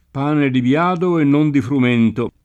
biada [ b L# da ]